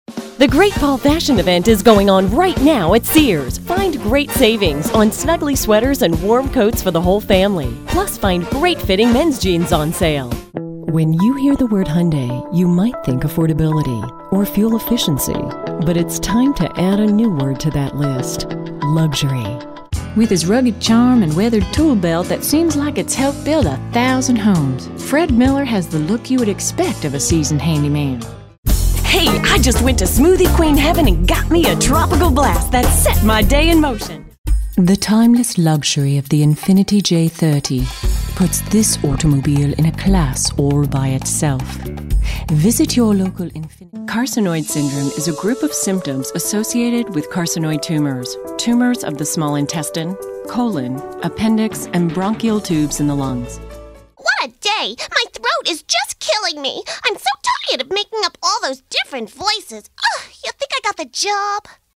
Female
English (North American)
Adult (30-50), Older Sound (50+)
Television Spots
Female Voice Over Talent